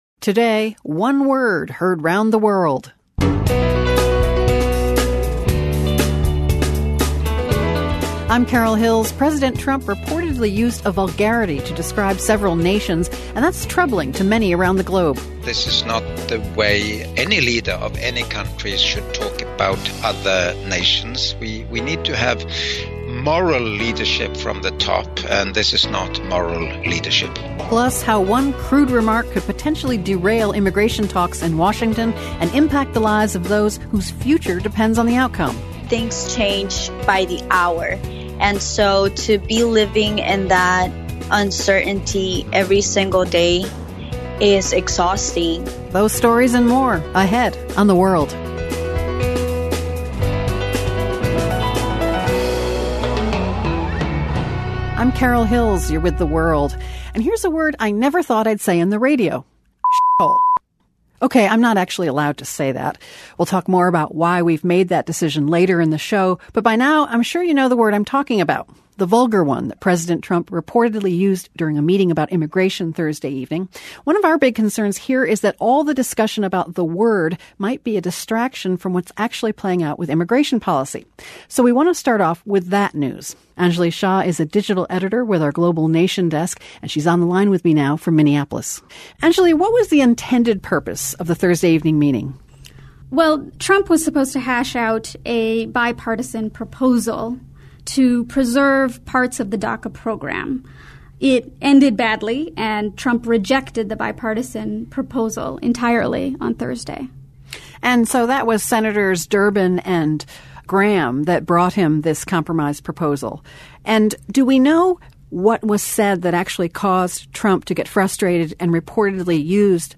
Global reaction to President Trump's reported use of vulgar language to discuss immigration. We hear from a DACA recipient whose future remains in limbo, an entrepreneur living and working in Haiti, and a former top UN official.